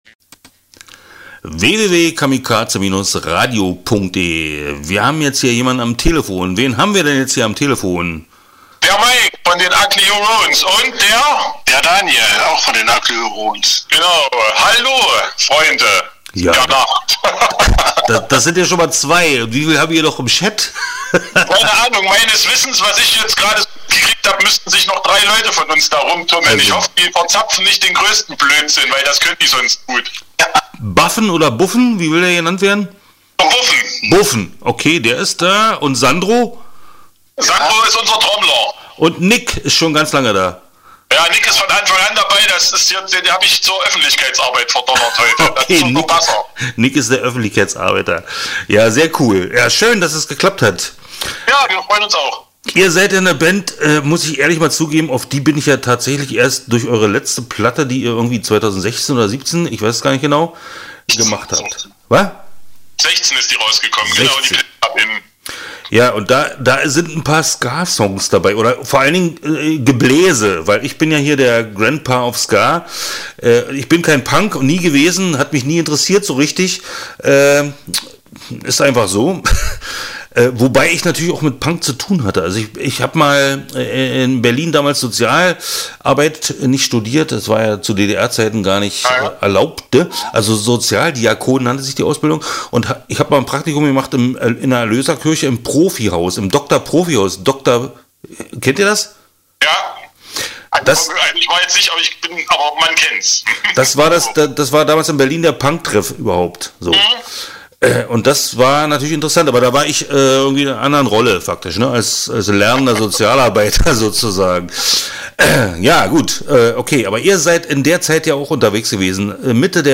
Start » Interviews » Ugly Hurons